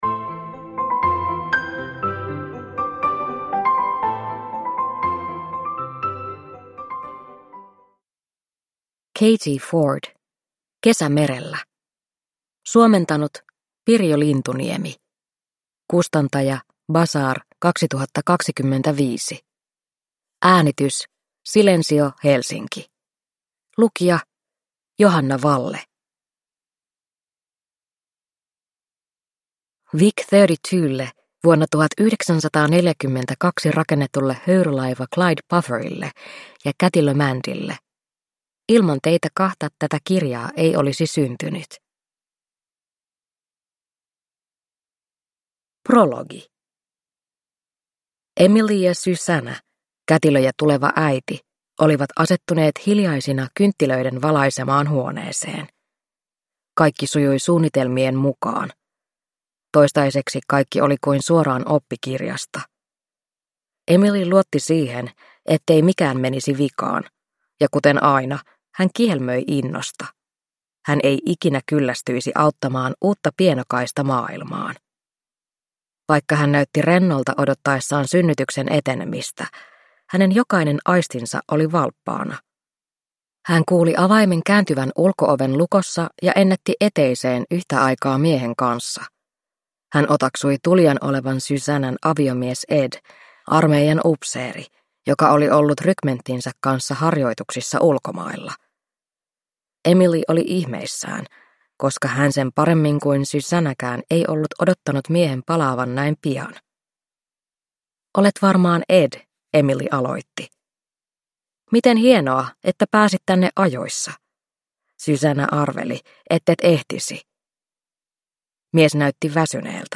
Kesä merellä – Ljudbok